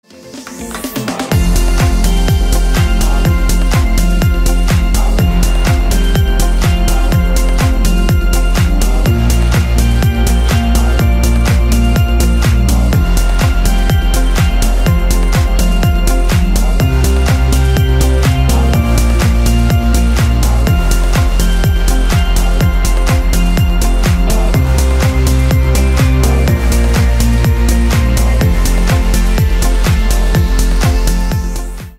Ремикс
кавказские # без слов # клубные